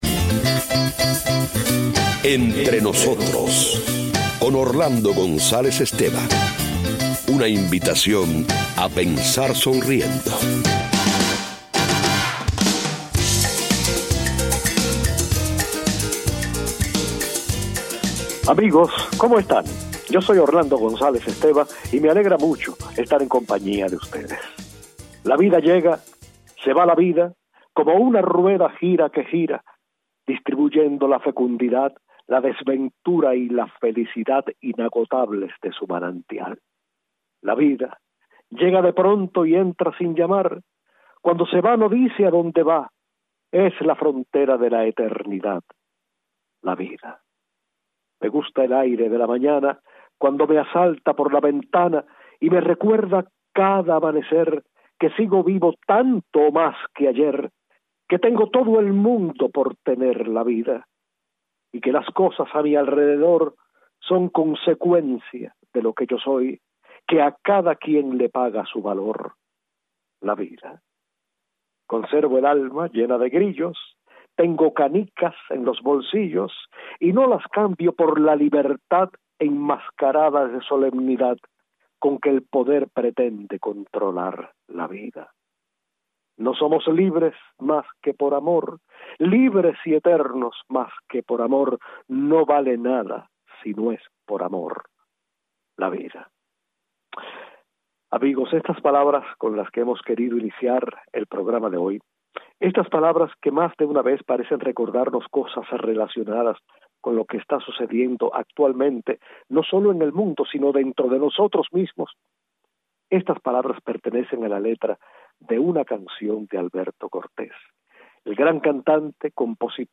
El gran intérprete y compositor argentino cumple un año de muerto y nosotros lo recordamos escuchando sus canciones de amor e intercalando entre ellas algunos versos de José Ángel Buesa.